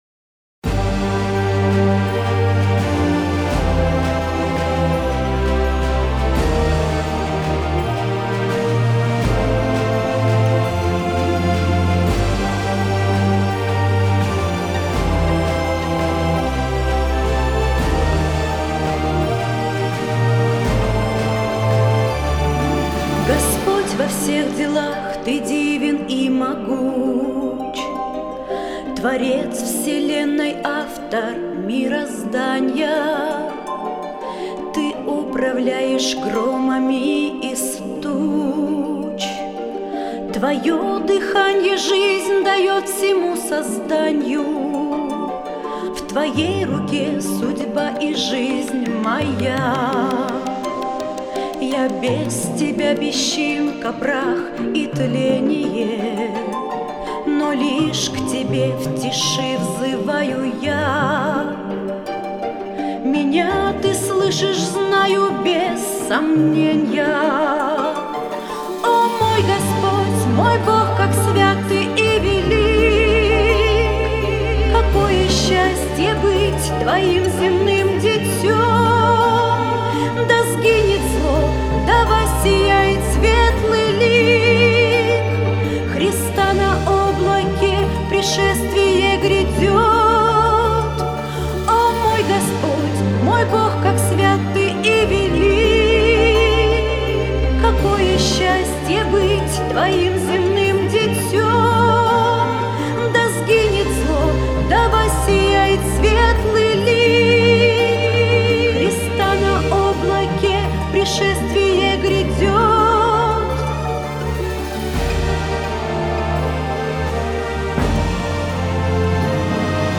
163 просмотра 333 прослушивания 23 скачивания BPM: 75